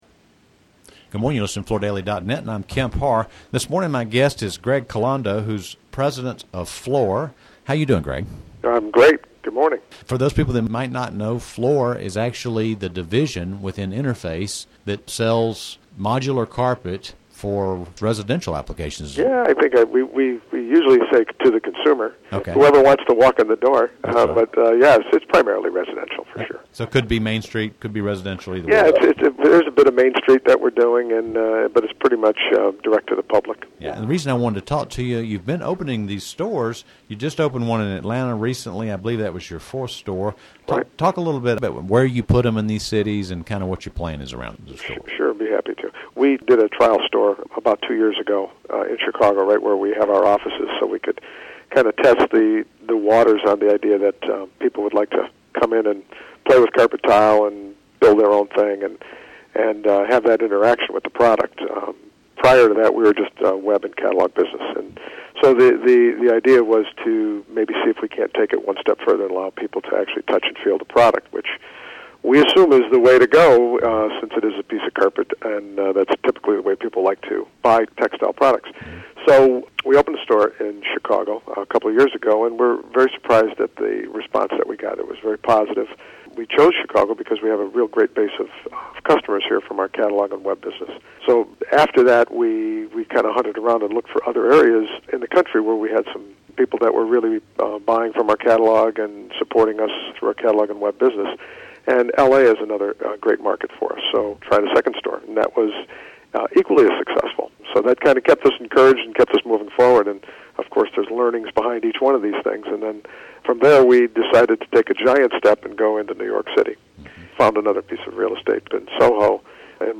Listen to the interview to hear more about how it picks store locations, as well as information on product styling and future plans.